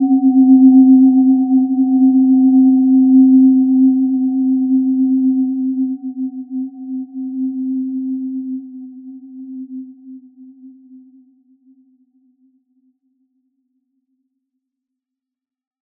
Gentle-Metallic-3-C4-mf.wav